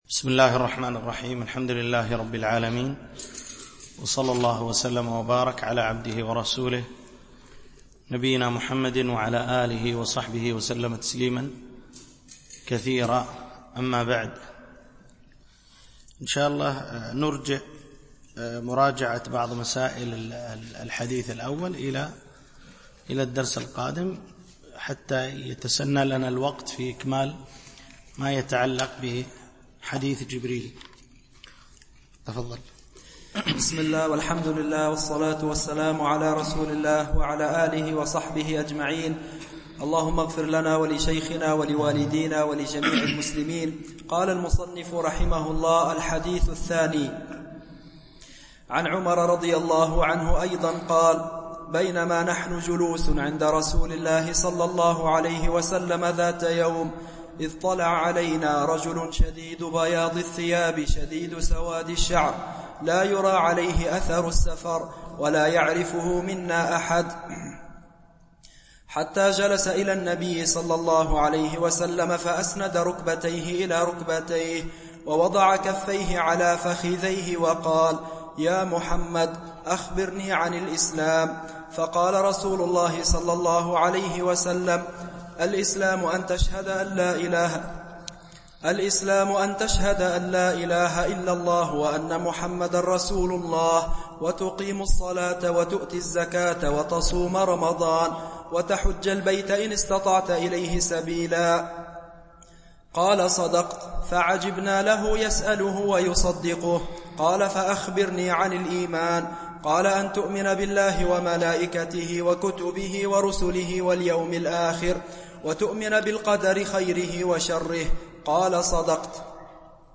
شرح الأربعين النووية - الدرس 2 ( الحديث 2 )